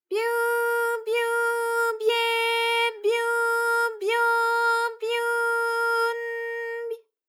ALYS-DB-001-JPN - First Japanese UTAU vocal library of ALYS.
byu_byu_bye_byu_byo_byu_n_by.wav